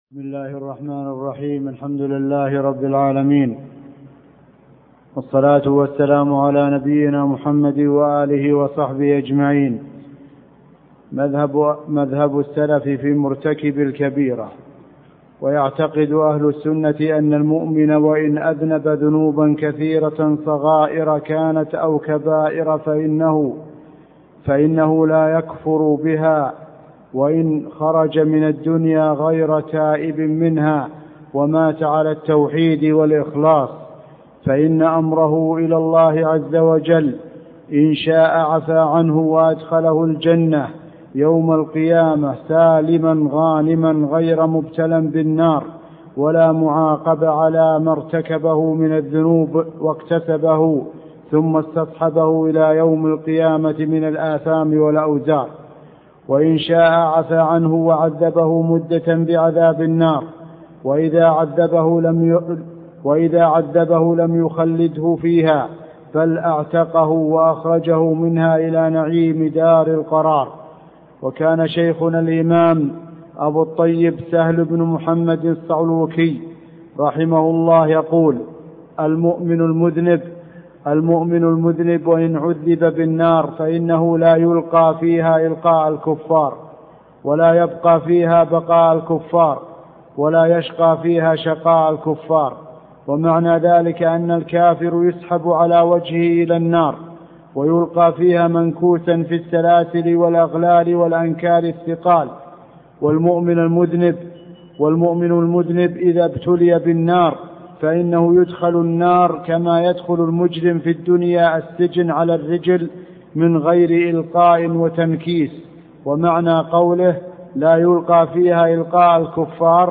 عنوان المادة الدرس (7) شرح عقيدة السلف أصحاب الحديث تاريخ التحميل الخميس 9 فبراير 2023 مـ حجم المادة 22.55 ميجا بايت عدد الزيارات 208 زيارة عدد مرات الحفظ 111 مرة إستماع المادة حفظ المادة اضف تعليقك أرسل لصديق